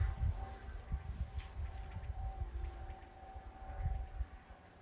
Common Quail, Coturnix coturnix
StatusSinging male in breeding season
NotesPaipalu saucieni bija dzirdami visu dienu.